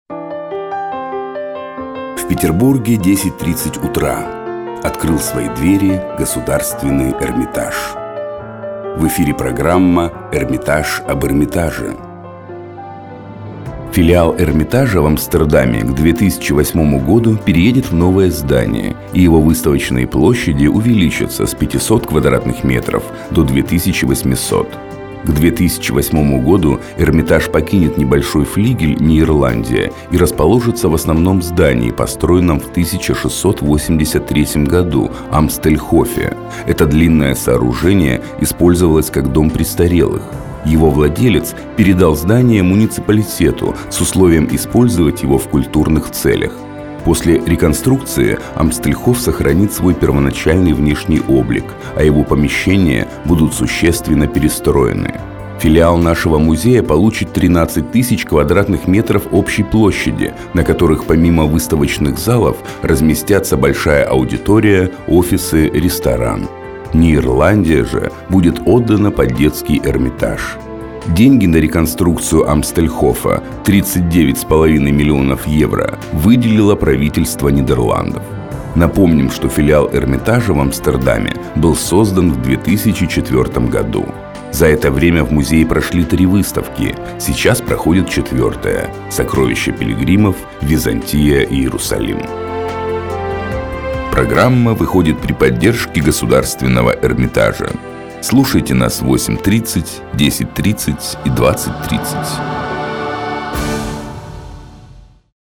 Мужской
Баритон